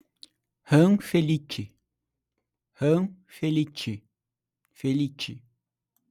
RAMPAGE – Rampeige
HORN – Rôrni
RT – falar letra por letra